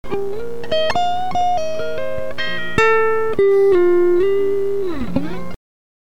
Compressed signal